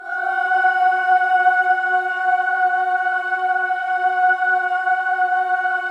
VOWEL MV12-L.wav